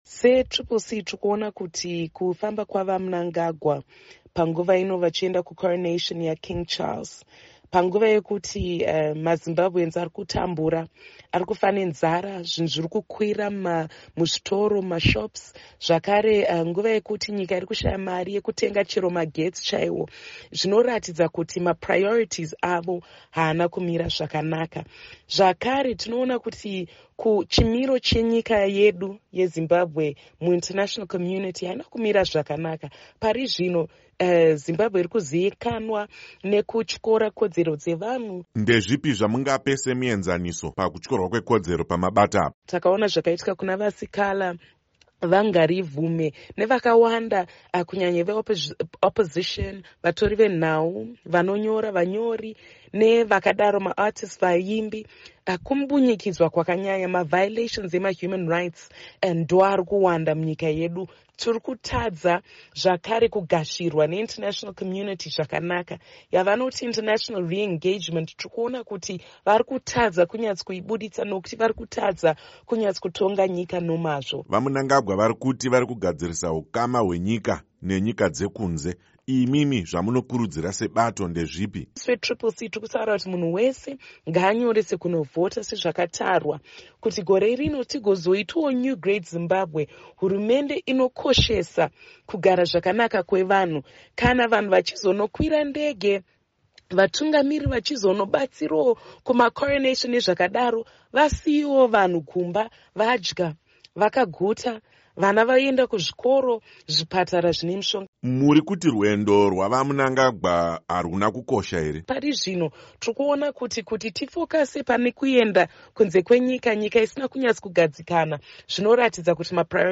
Hurukuro naMuzvare Fadzai Mahere